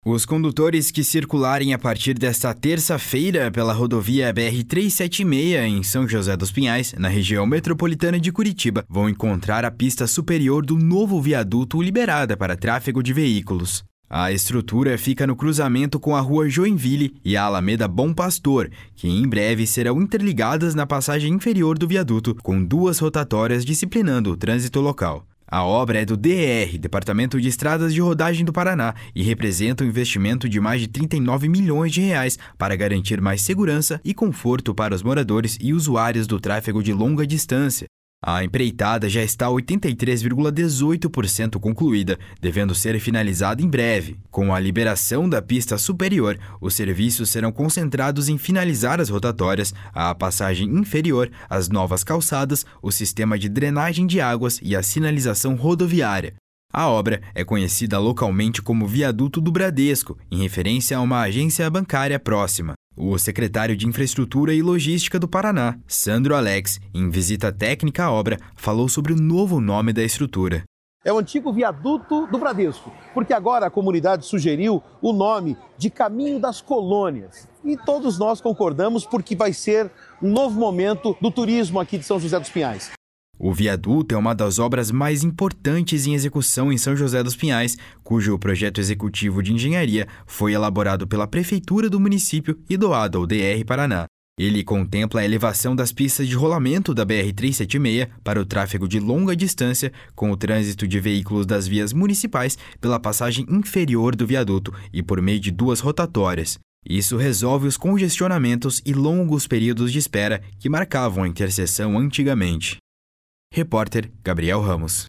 O secretário de Infraestrutura e Logística do Paraná, Sandro Alex, em visita técnica à obra, falou sobre o novo nome da estrutura. // SONORA SANDRO ALEX //